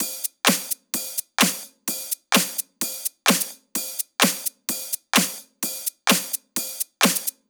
VDE 128BPM Close Drums 4.wav